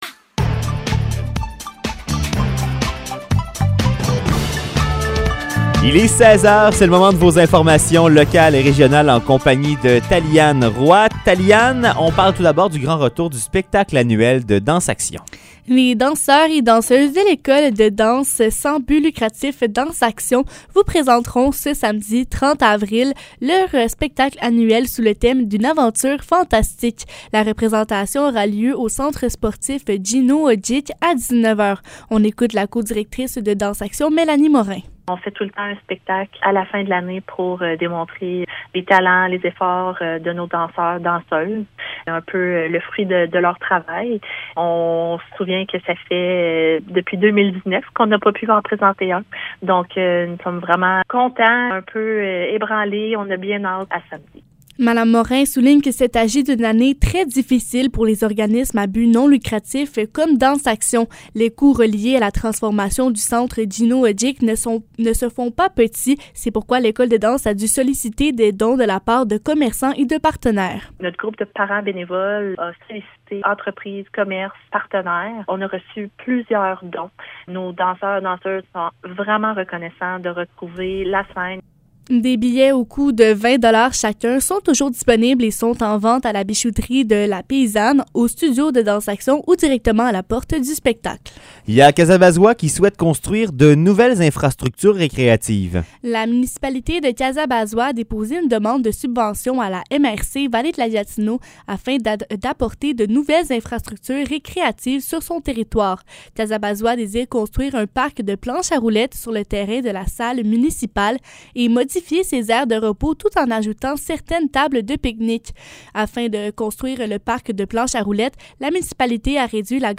Nouvelles locales - 27 avril 2022 - 16 h